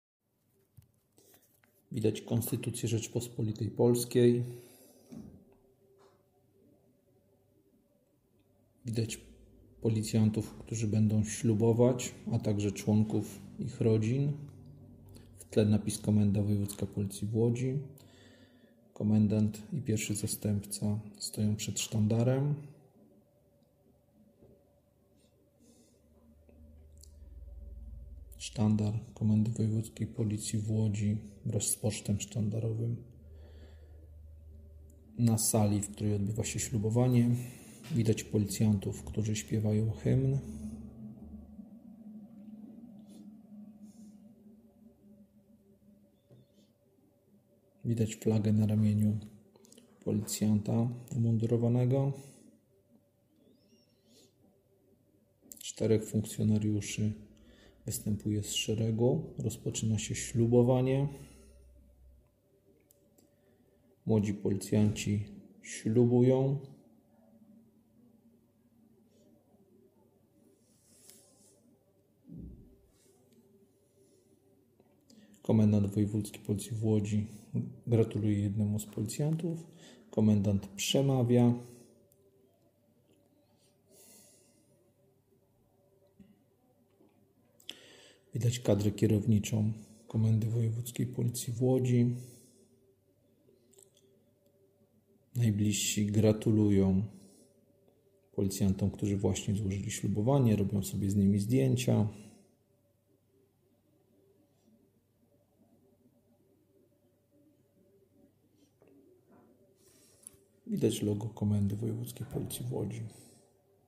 4 czerwca 2025 roku na auli Komendy Wojewódzkiej Policji w Łodzi odbyło się uroczyste ślubowanie 25 nowo przyjętych policjantów. Adepci sztuki policyjnej wypowiedzieli słowa roty ślubowania.
Wprowadzono Sztandar Komendy Wojewódzkiej Policji w Łodzi, odtworzono Hymn RP, przywitano przybyłych gości.
Nastąpił podniosły moment, podczas którego przyjmujący ślubowanie insp. Arkadiusz Sylwestrzak Komendant Wojewódzki Policji w Łodzi, odczytał rotę ślubowania, którą powtórzyli za nim ślubujący policjanci.
Nagranie audio slubowanie.mp3